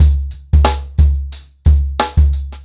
TheStakeHouse-90BPM.3.wav